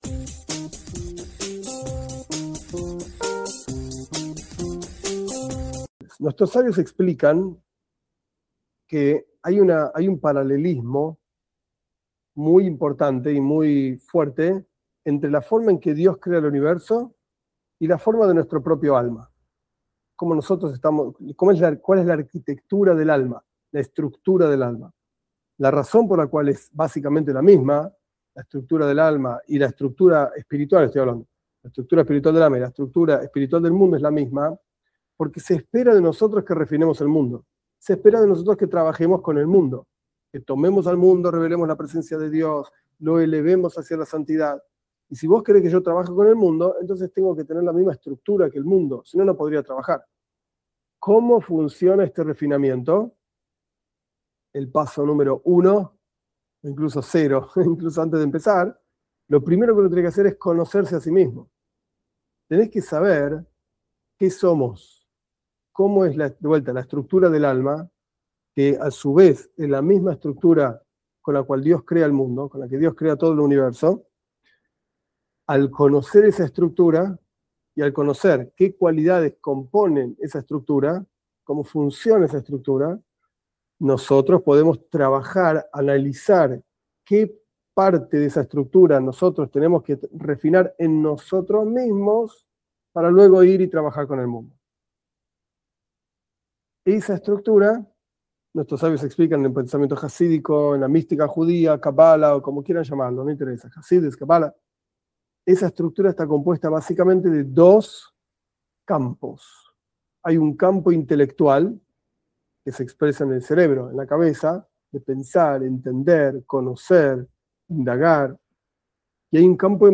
Esta es una parte de una clase que explica, brevemente, la estructura del alma y por qué es así.